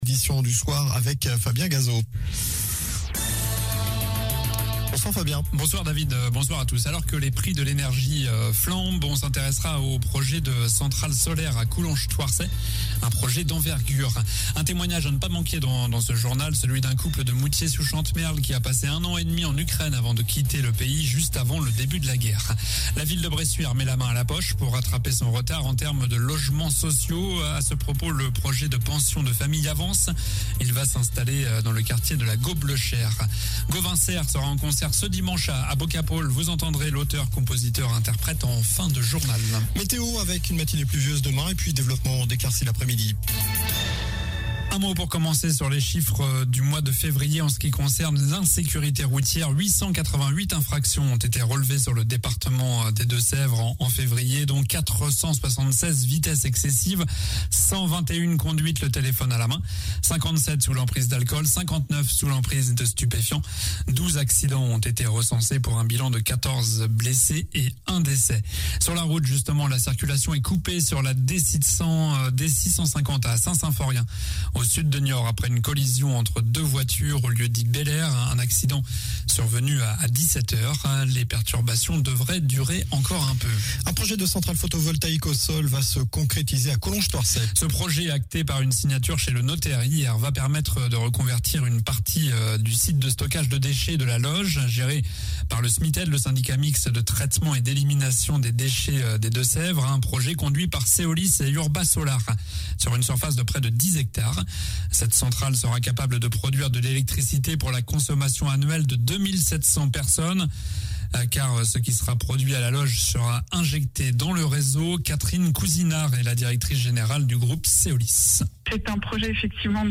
Journal du jeudi 10 mars (soir)